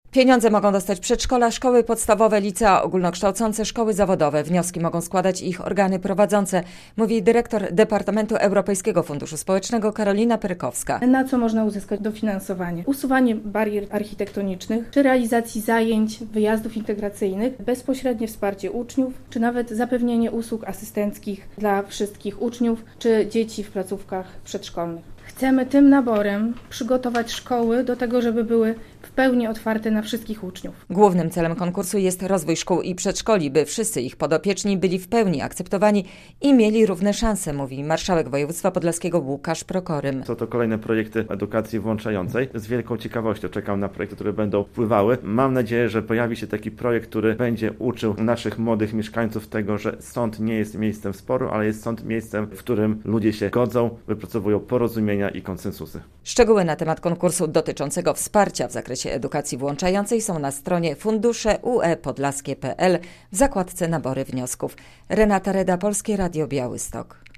O konkursie dla szkół i przedszkoli w zakresie edukacji włączającej - relacja